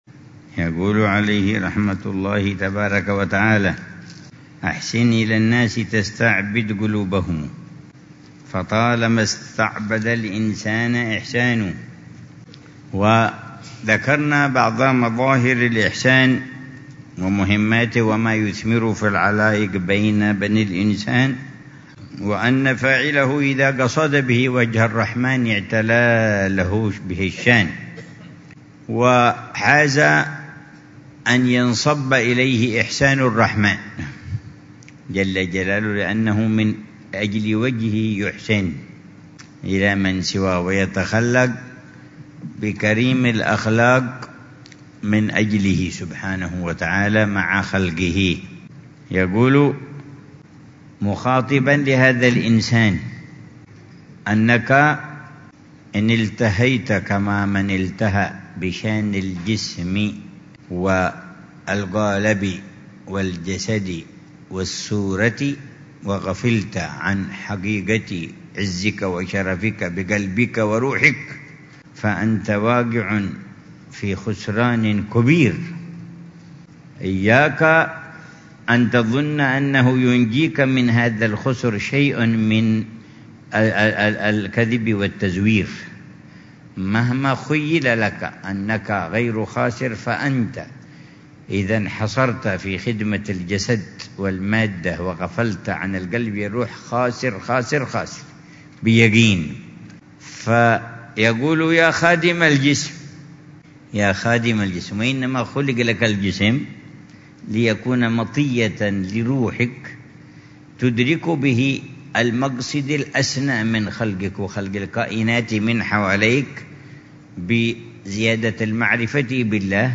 الدرس الرابع من شرح العلامة الحبيب عمر بن محمد بن حفيظ لقصيدة الإمام أبي الفتح البستي - عنوان الحِكم ، التي مطلعها ( زيادة المرء في دنياه ن